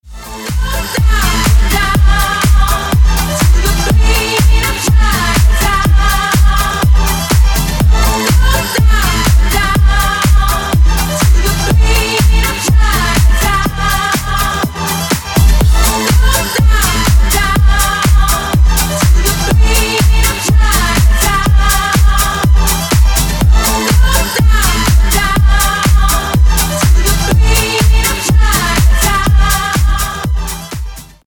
веселые
женский голос
Club House
скрипка
ремиксы